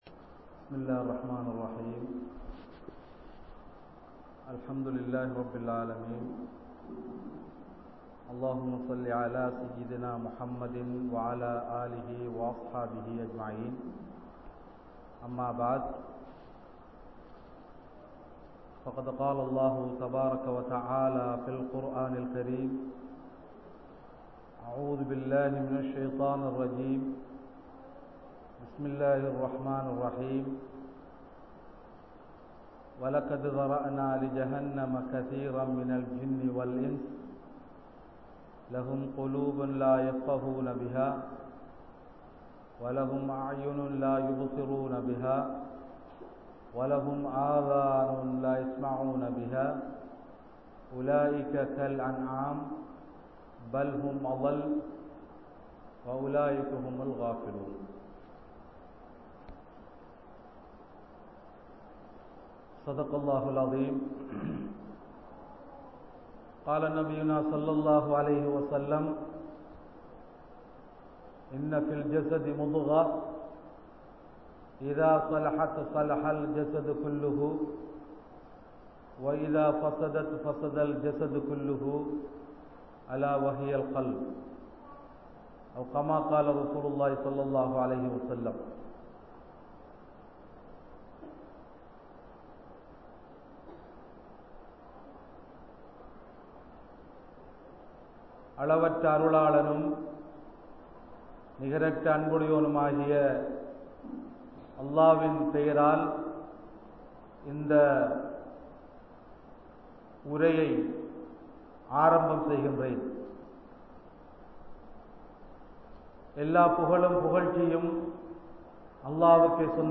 "Ullaththitkaana Unavuhal" (உள்ளத்திற்கான உணவுகள்) | Audio Bayans | All Ceylon Muslim Youth Community | Addalaichenai